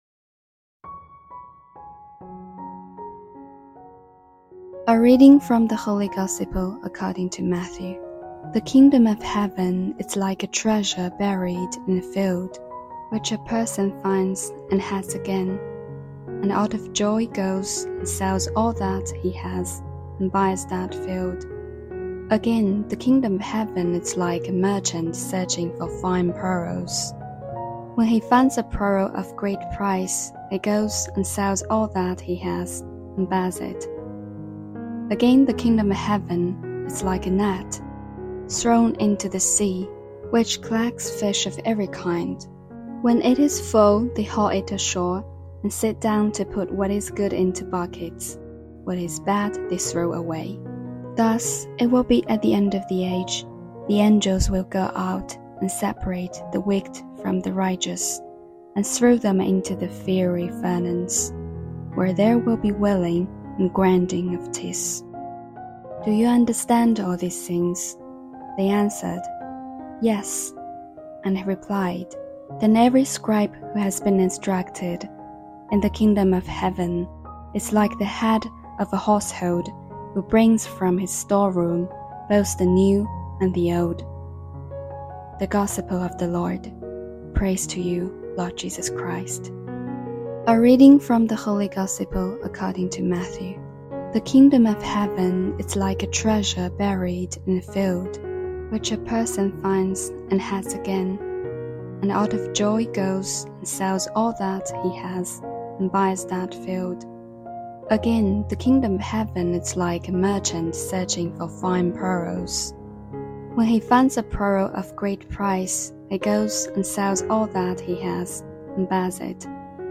HomilyTHE JOY OF DISCOVERING A TREASURE